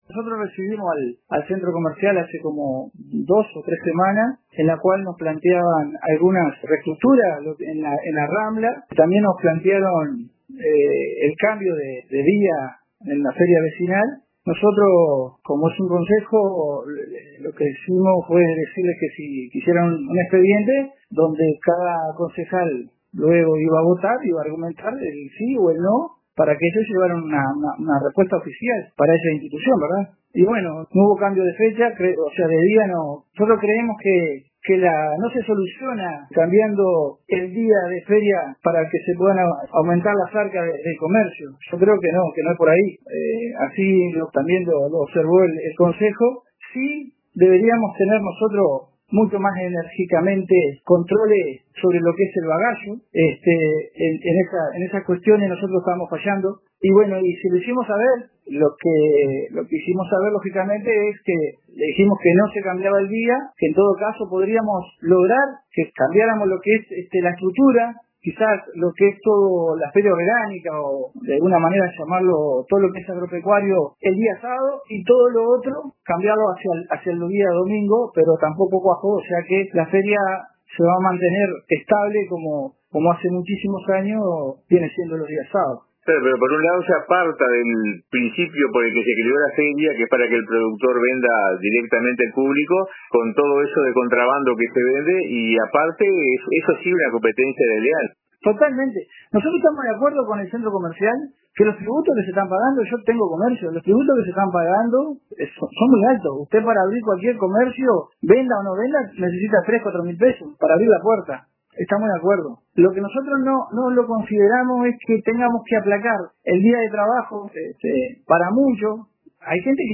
Alberto Miranda, Concejal representante del Frente Amplio en el Municipio de Piriápolis, compartió sus opiniones con el informativo de RADIO RBC. En su declaración, informó que la propuesta de cambiar el día de la feria vecinal de Piriápolis no obtuvo aprobación, no obstante, resaltó la importancia de intensificar los controles para transformar la feria, que en la actualidad se ha convertido en un espacio con actividades de contrabando, en lugar de comercialización legítima.